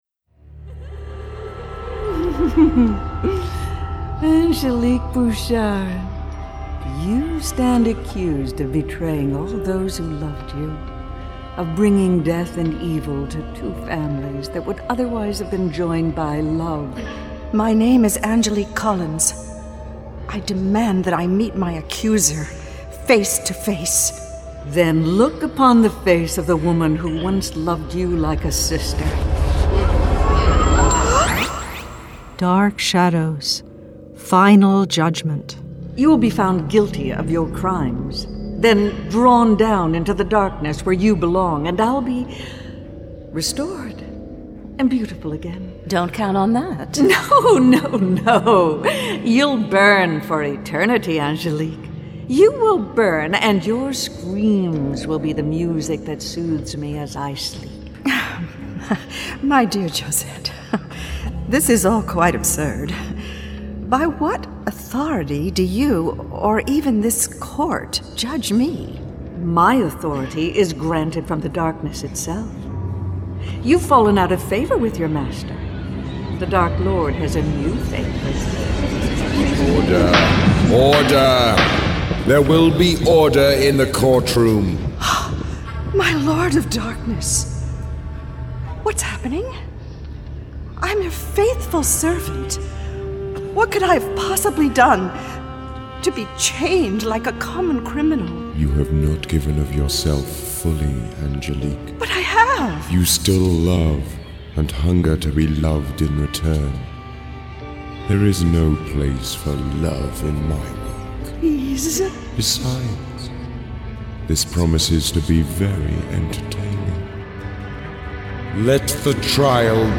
Dark Shadows: The Horror Collection - Dark Shadows - Dramatised Readings
Starring Lara Parker Kathryn Leigh Scott